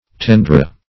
Search Result for " tendre" : The Collaborative International Dictionary of English v.0.48: Tendre \Ten"dre\, n. [F.]